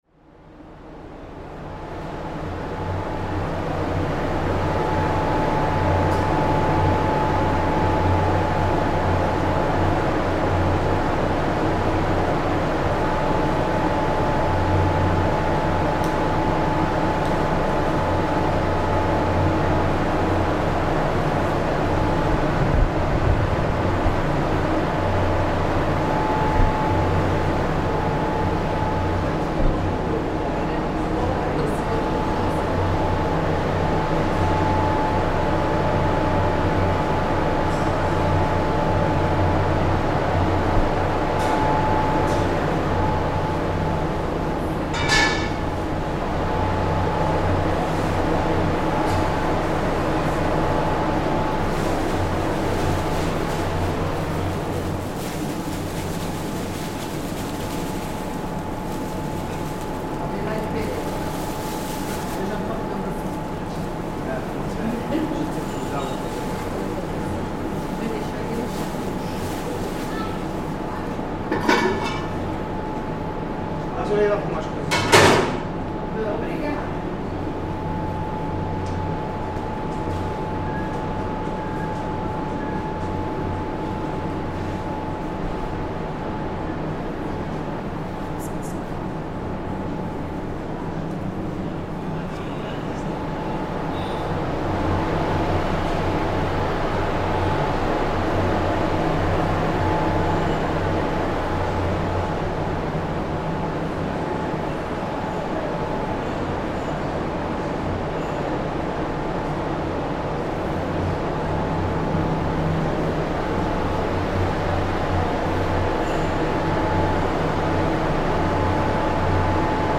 Recording from the Cha Gorreana tea factory in the Azores, the largest tea plantation in Europe - we hear freshly-picked tea leaves from the plantation being poured into a rotating drum, a mixing machine that prepares the leaves for the next stage in a process conducted entirely by hand.